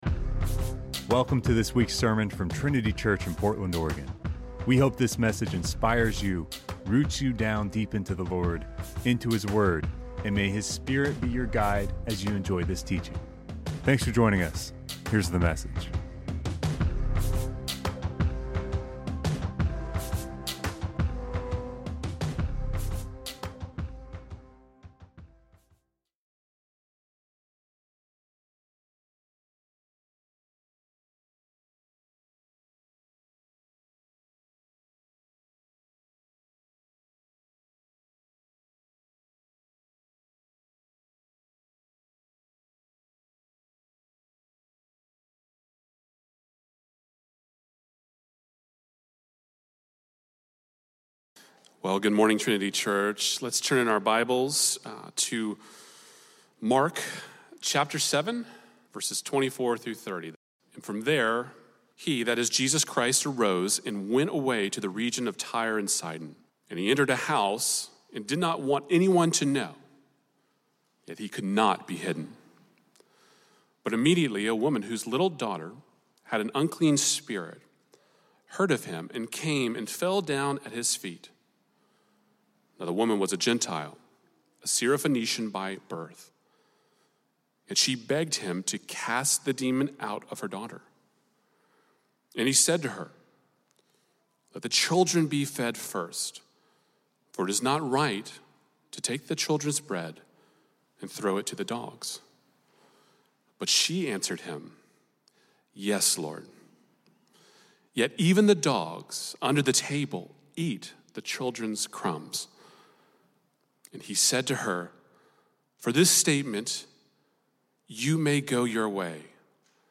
Welcome to this week’s sermon from Trinity Church in Portland, Oregon.